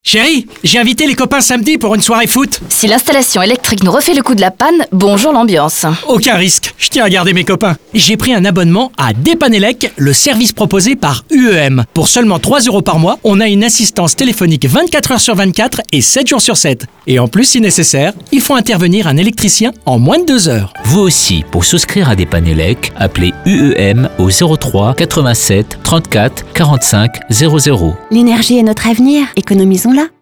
SPOT-RADIO-UEM-Dépann-Elec-oct-2017.wav